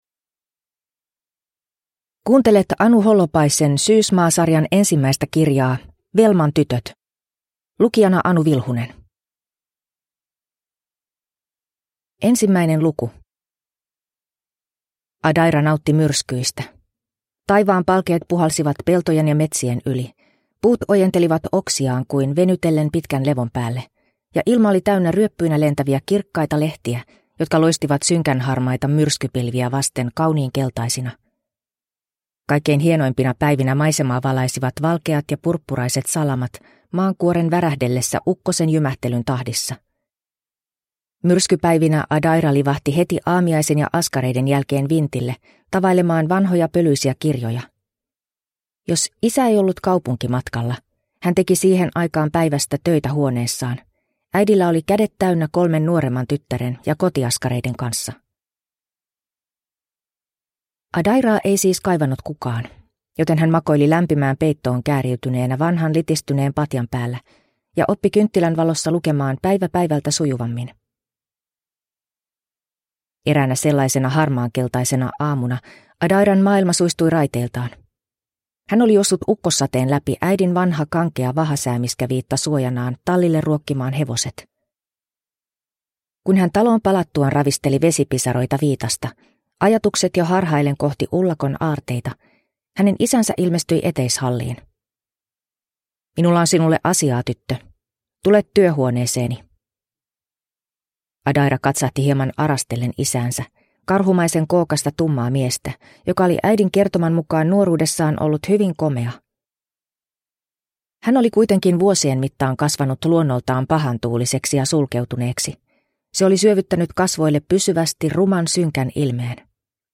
Welman tytöt – Ljudbok